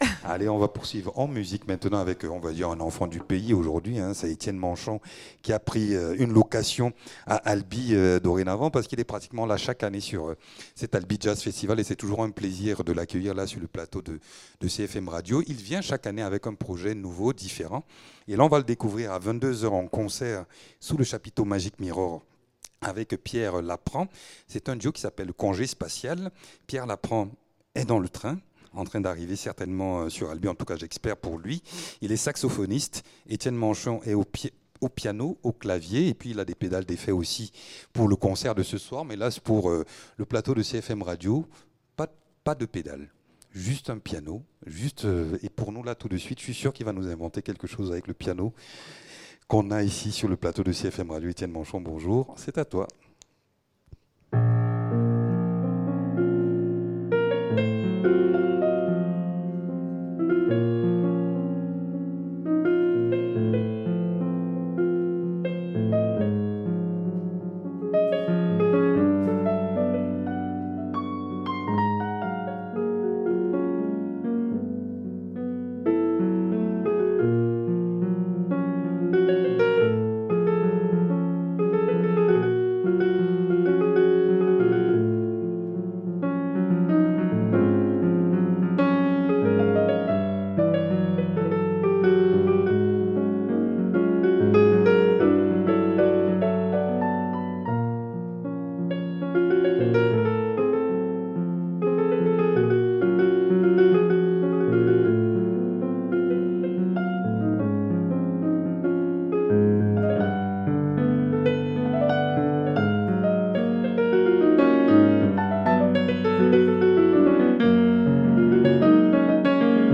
pianiste et compositeur.